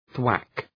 {twæk}
thwack.mp3